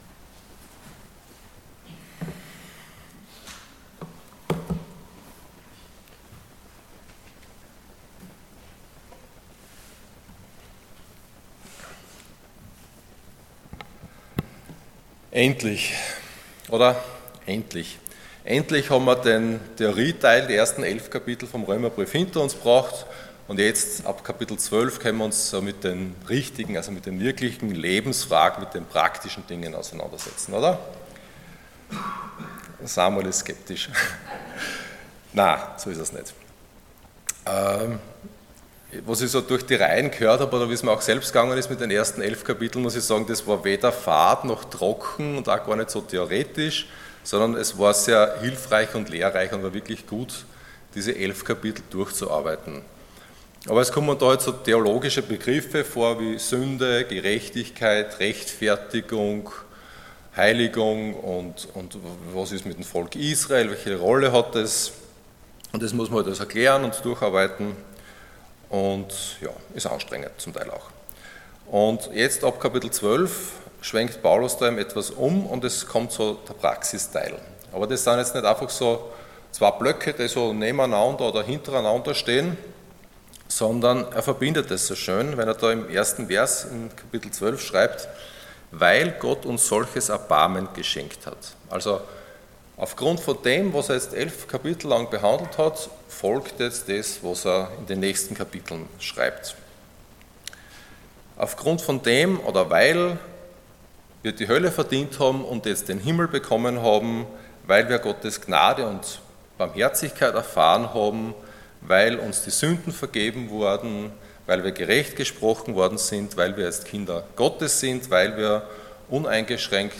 Passage: Römer 12,3-8 Dienstart: Sonntag Morgen